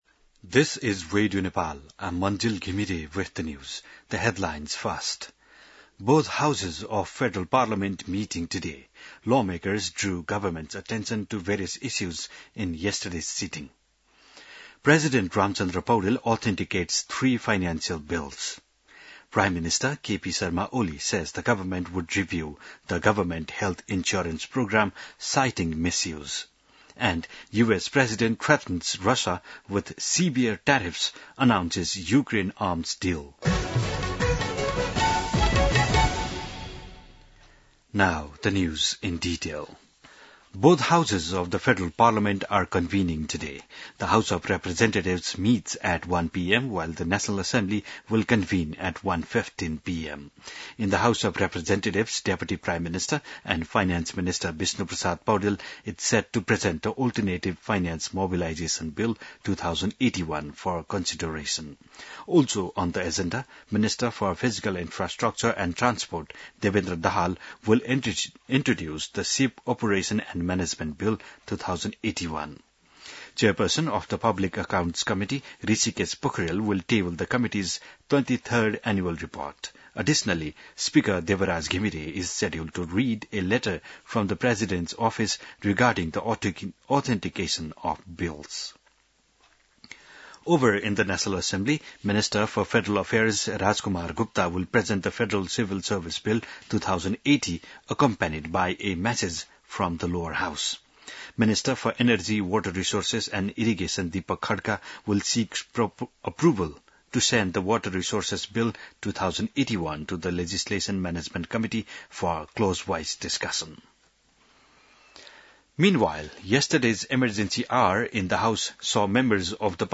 बिहान ८ बजेको अङ्ग्रेजी समाचार : ३१ असार , २०८२